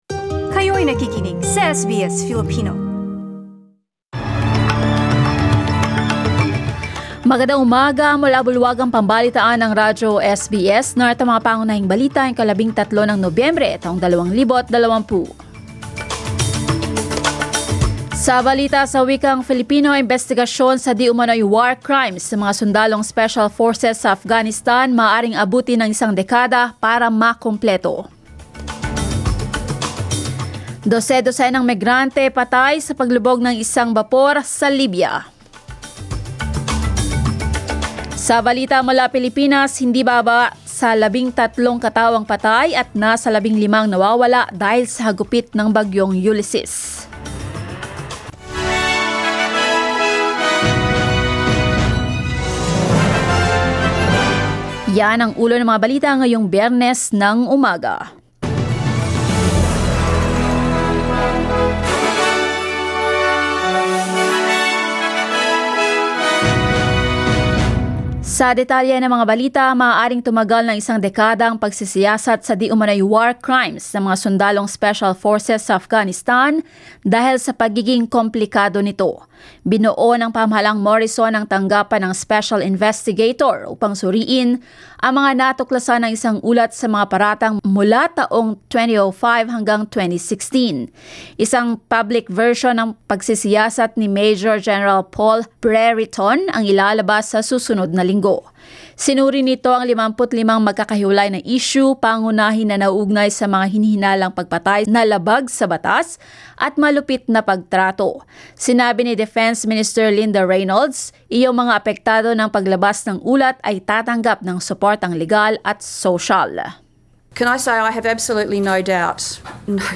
SBS News in Filipino, Friday 13 November